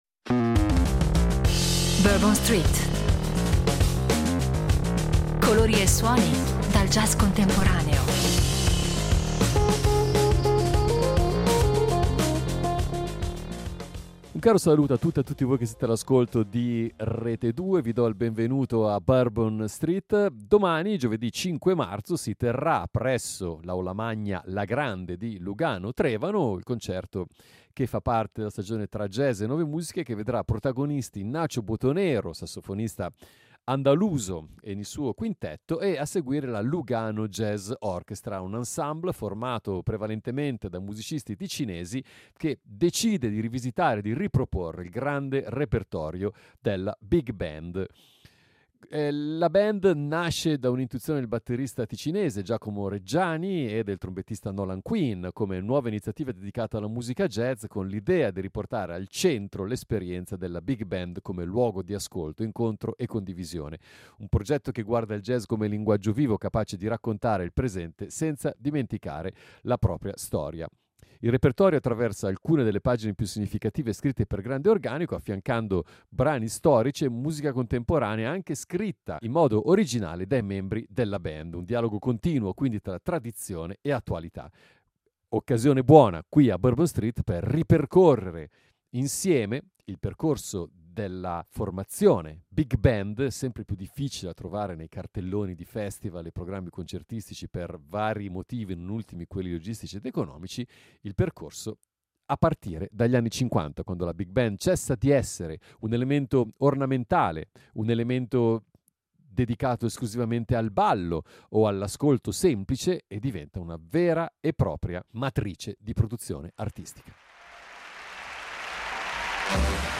La trasmissione propone un ascolto guidato che mette in luce le metamorfosi dell’orchestra jazz: dalla precisione architettonica alla libertà espressiva, dalla scrittura orchestrale alla dimensione narrativa del suono.